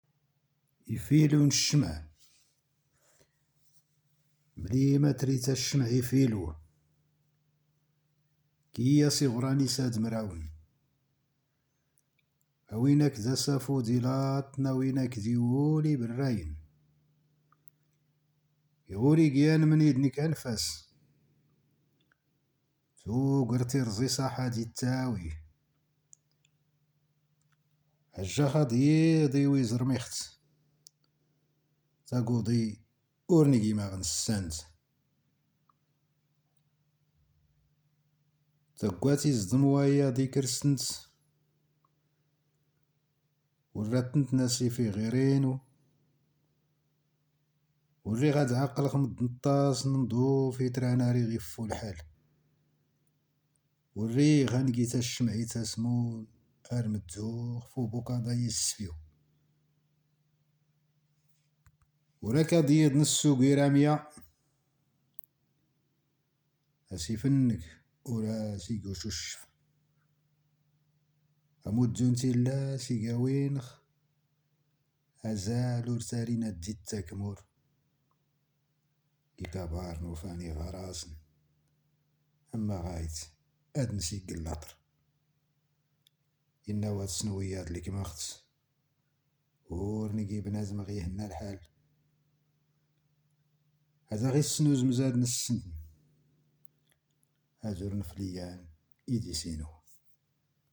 تامديازت/شعر